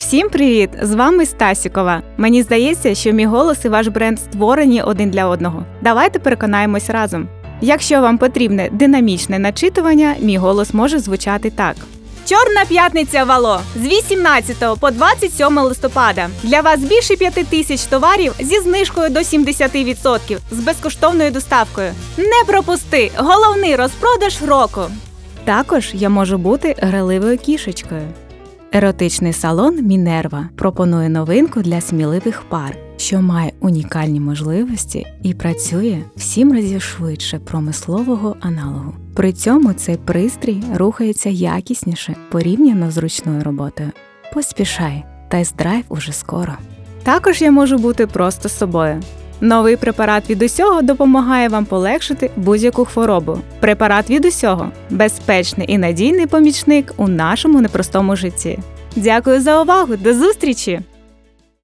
Портфоліо радіоведучої.mp3
Жіноча